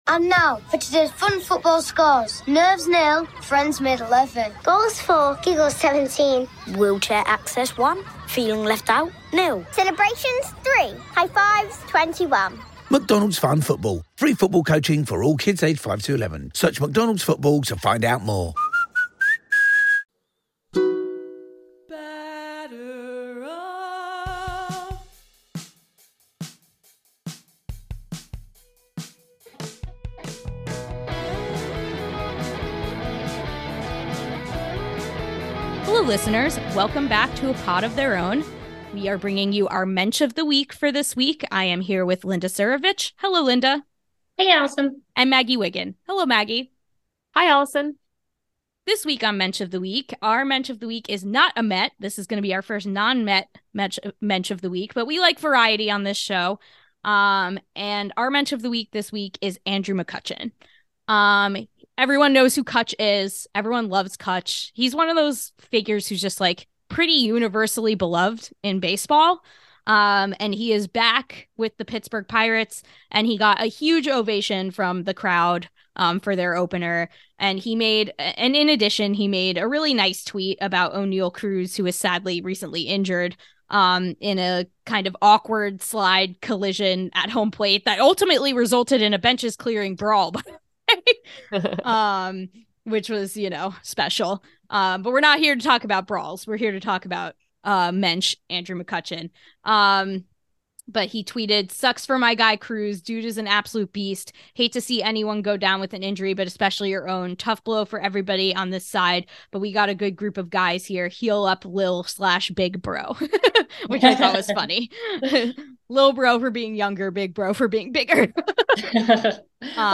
Welcome back to A Pod of Their Own, an all-women led Home Run Applesauce podcast where we talk all things Mets, social justice issues in baseball, and normalize female voices in the sports podcasting space.